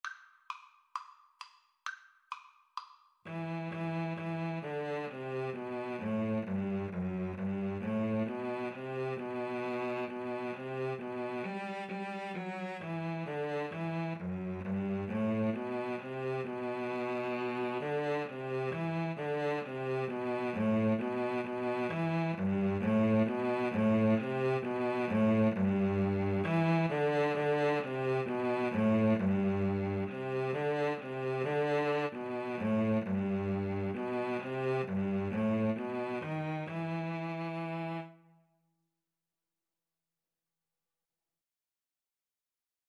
Play (or use space bar on your keyboard) Pause Music Playalong - Player 1 Accompaniment reset tempo print settings full screen
Slow two in a bar feel = c. 66
E minor (Sounding Pitch) (View more E minor Music for Violin-Cello Duet )